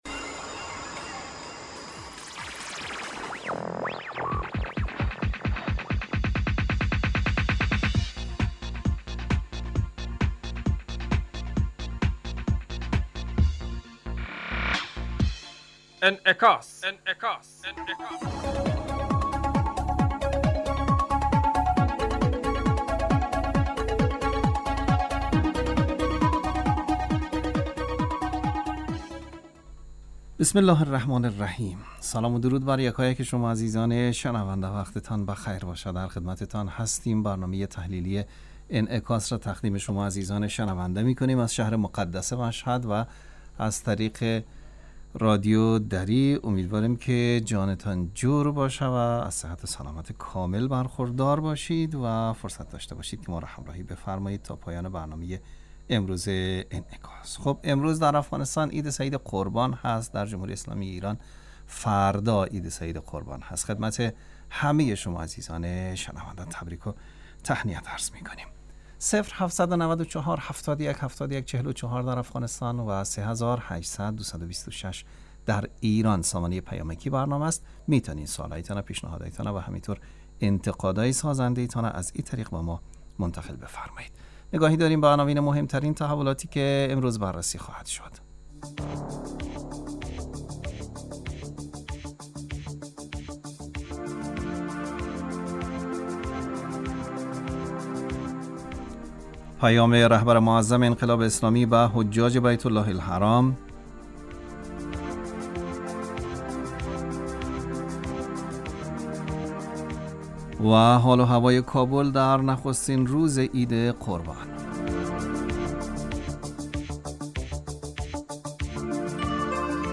برنامه انعکاس به مدت 35 دقیقه هر روز در ساعت 18:55 بعد ظهر بصورت زنده پخش می شود. این برنامه به انعکاس رویدادهای سیاسی، فرهنگی، اقتصادی و اجتماعی مربوط به افغانستان و تحلیل این رویدادها می پردازد.